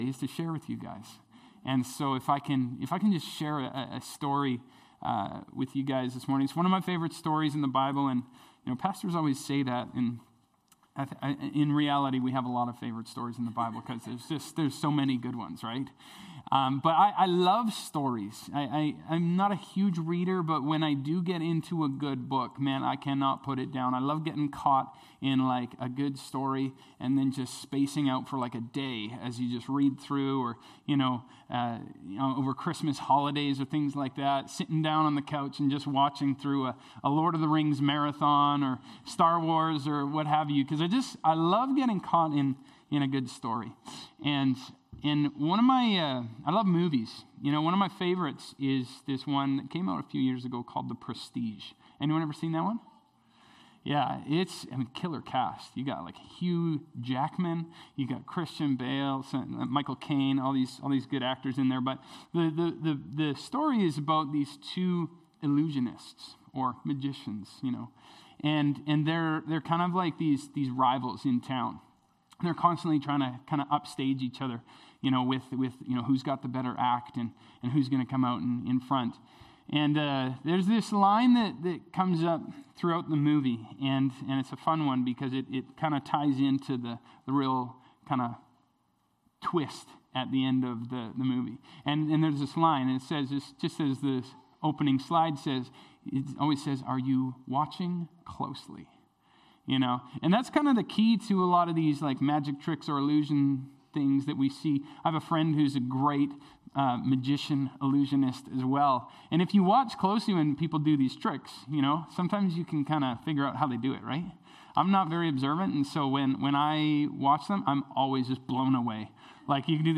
Sermons | Terrace Pentecostal Assembly